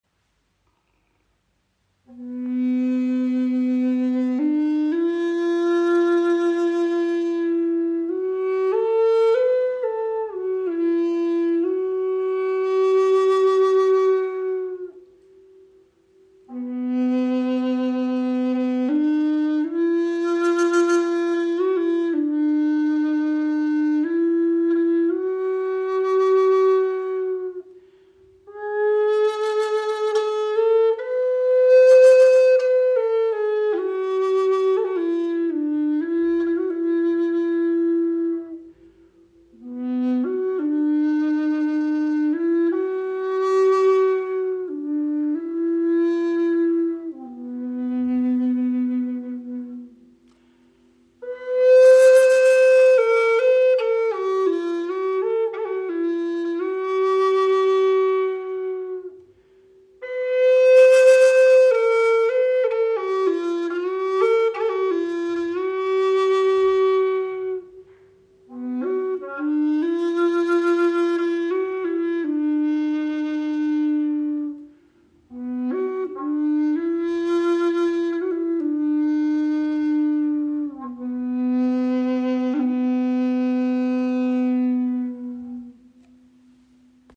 Gebetsflöte in tiefem C - 432 Hz
• Icon Aus Cerejeira
• Icon 70 cm lang, 6 Grifflöcher